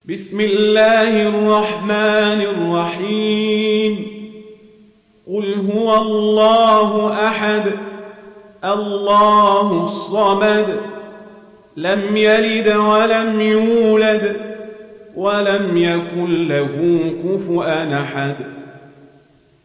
دانلود سوره الإخلاص mp3 عمر القزابري روایت ورش از نافع, قرآن را دانلود کنید و گوش کن mp3 ، لینک مستقیم کامل